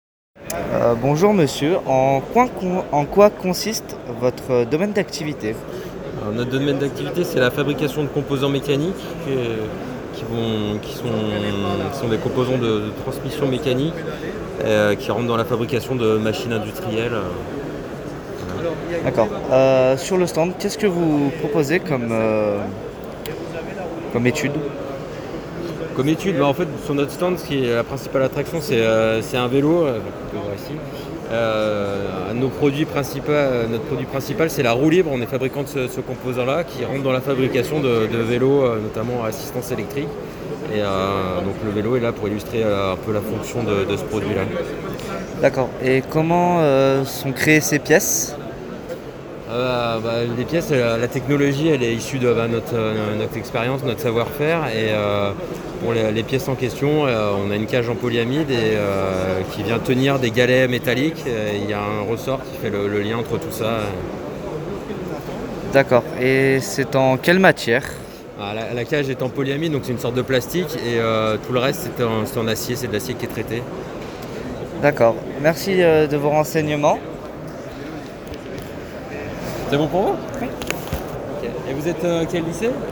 Puis écoutez une interview très intéressante d'une entreprise qui produit des pièces métalliques pour les velos etc... (voir pièce jointes SIAM INTERVIEW )